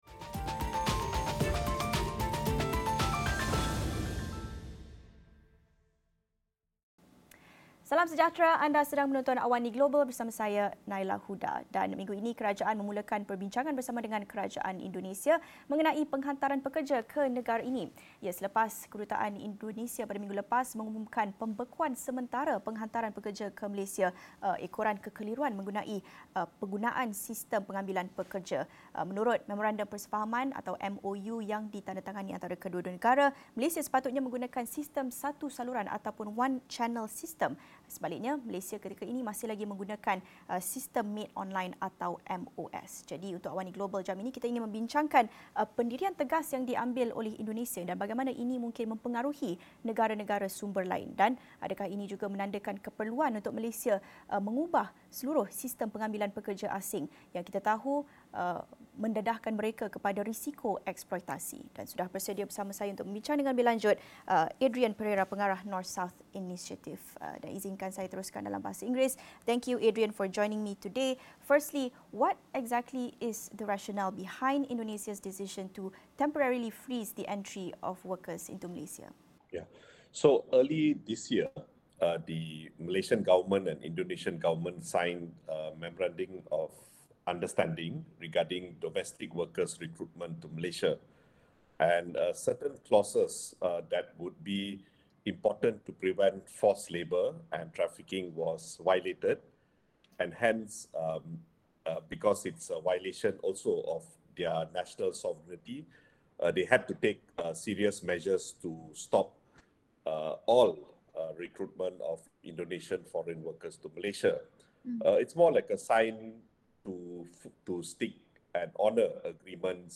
Apakah sudah tiba masa untuk menukar sistem pengambilan pekerja asing yang melindungi kebajikan dan hak pekerja? Ikuti diskusi dan analisis isu pekerja asing yang sentiasa berhadapan dengan eksploitasi pemerdagangan dan buruh paksa